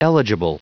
Prononciation du mot eligible en anglais (fichier audio)
Prononciation du mot : eligible